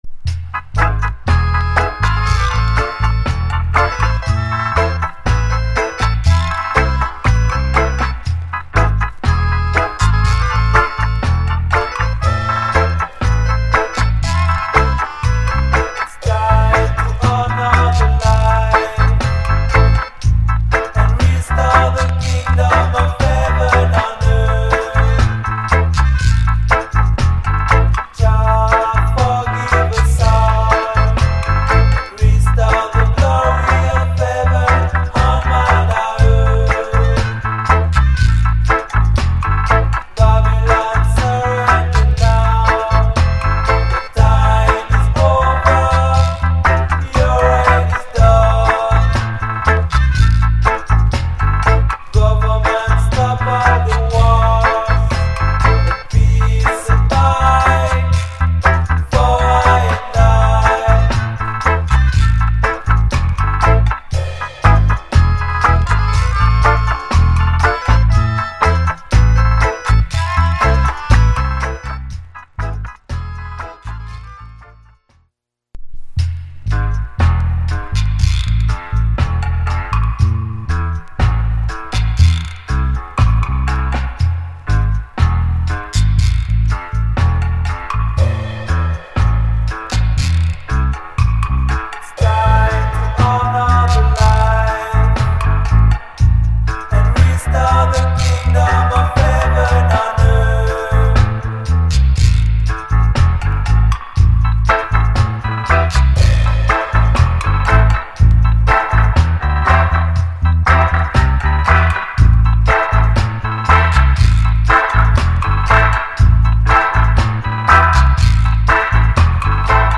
Genre Roots Rock / Male Vocal Group Vocal